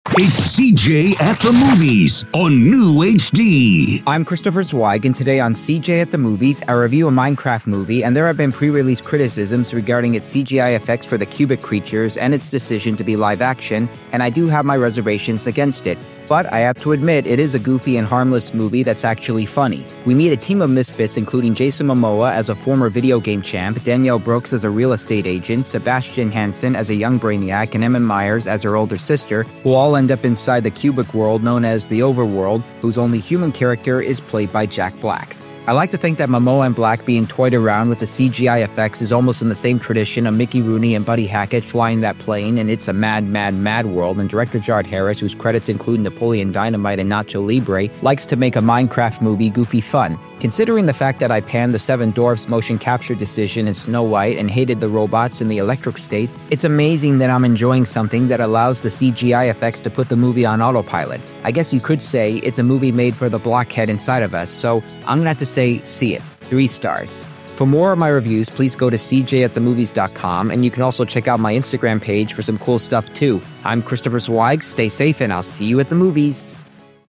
I’m part of a radio station known as NEWHD Radio, which not only hires people on the Autism spectrum, but also has me doing podcast movie reviews for them.